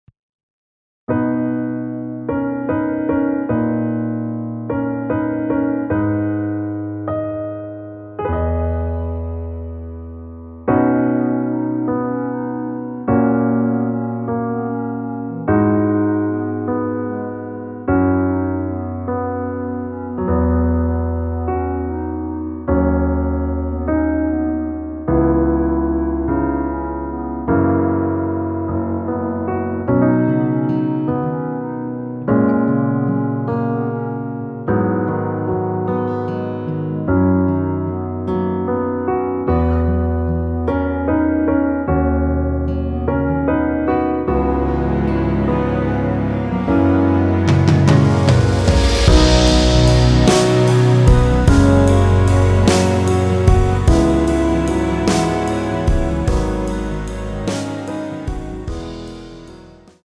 끝없는 밤을 걷자후에 2절의 나의 모든 날들을로 진행이 됩니다.
◈ 곡명 옆 (-1)은 반음 내림, (+1)은 반음 올림 입니다.
앞부분30초, 뒷부분30초씩 편집해서 올려 드리고 있습니다.
중간에 음이 끈어지고 다시 나오는 이유는